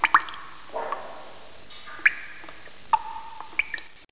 00043_Sound_Drip.mp3